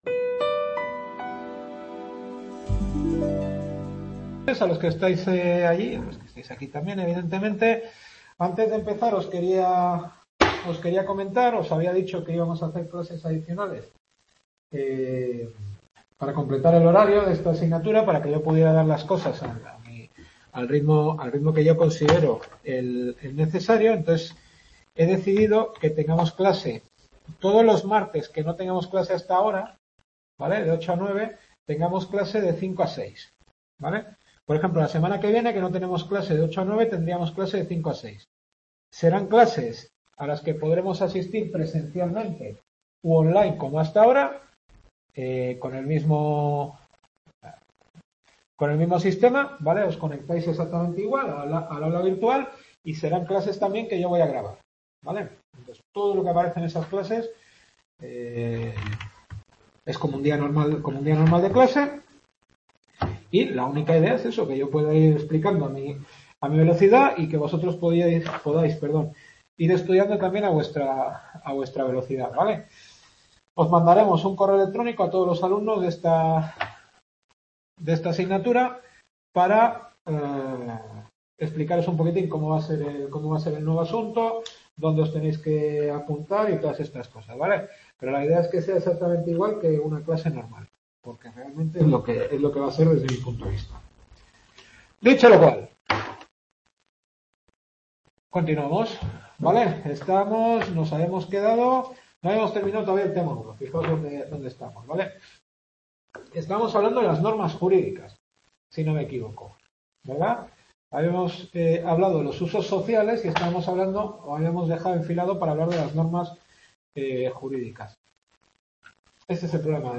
Teoría del Derecho, Segunda clase.
Campus Nordeste